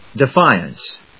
/dɪfάɪəns(米国英語)/